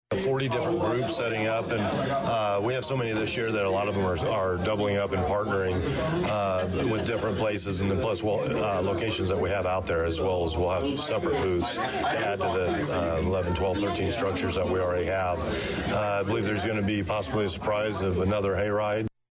Another event mentioned is this Saturday’s (Oct 25th) BOO BASH at Lincoln Park and Friendly Town, 5 – 7 PM.  Danville Police Chief Christopher Yates says that once again a full team will offer families a great time.